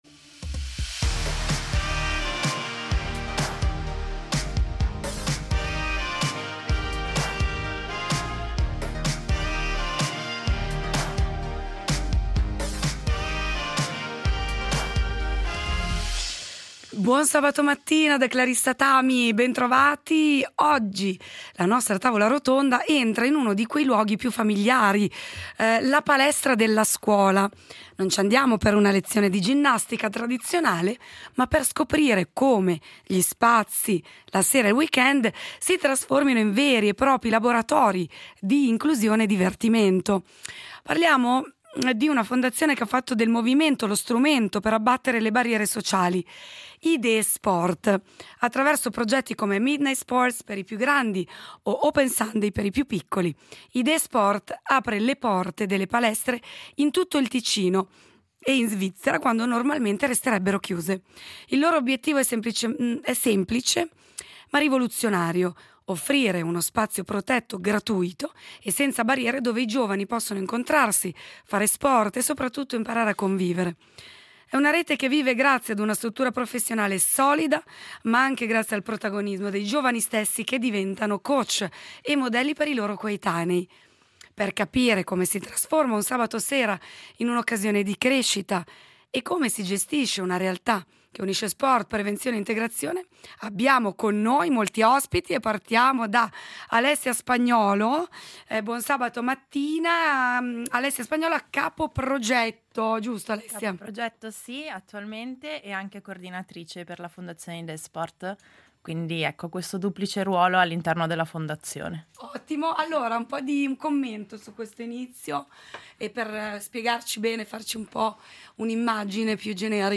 In studio le voci della Fondazione IdéeSport .